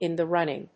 アクセントin [òut of] the rúnning